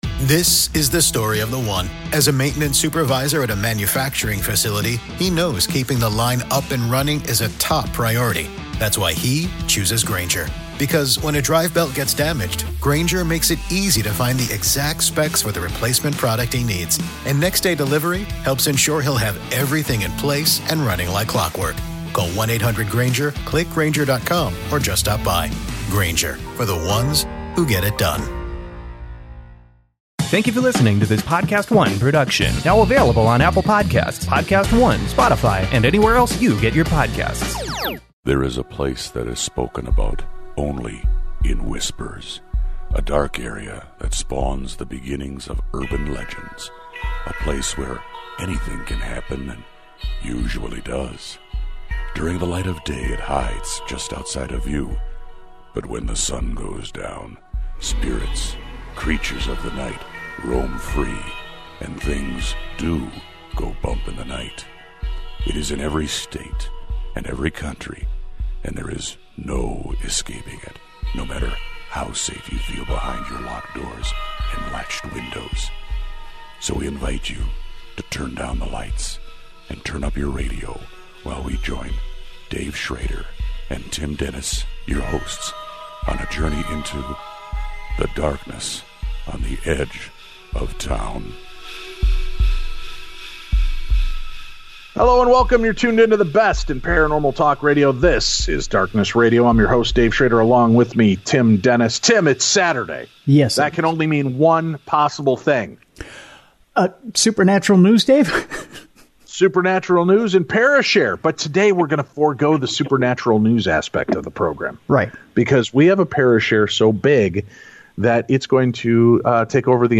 paranormal talk radio